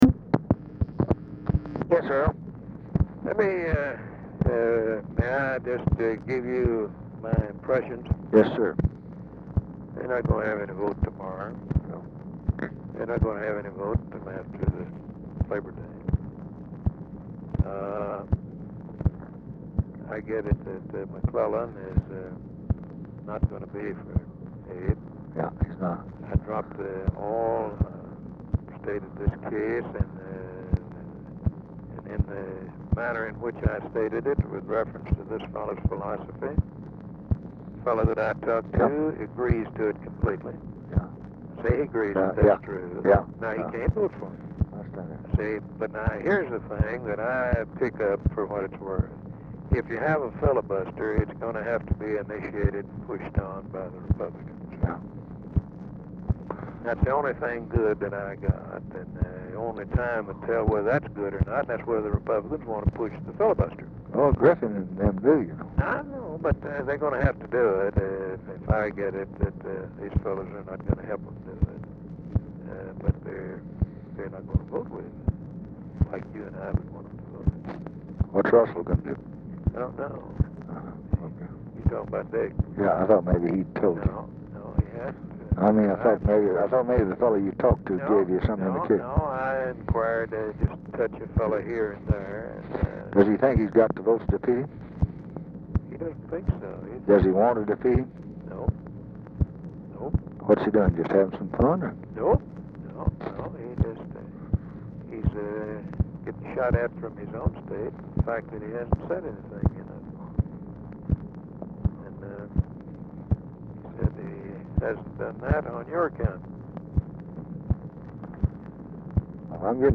Telephone conversation # 13221, sound recording, LBJ and EARLE CLEMENTS, 7/30/1968, 2:31PM | Discover LBJ
Format Dictation belt
Location Of Speaker 1 Mansion, White House, Washington, DC
Specific Item Type Telephone conversation